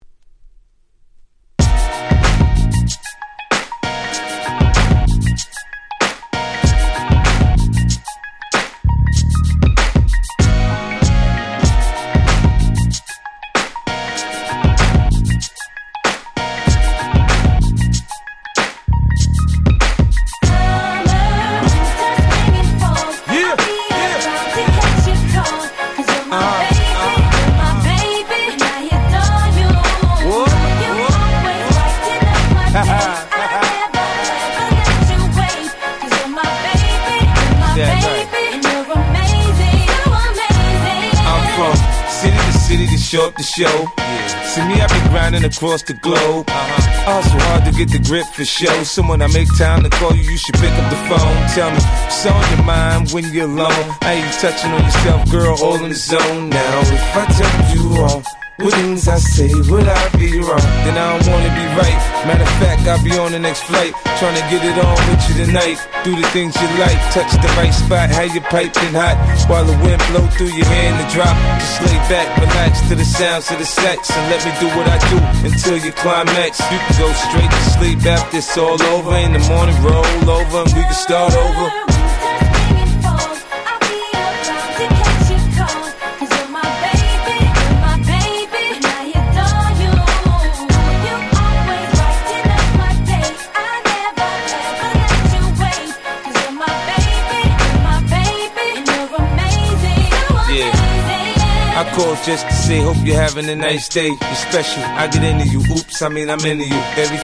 DJ用にイントロにBreakがついて繋ぎ易くなってたりするアレです。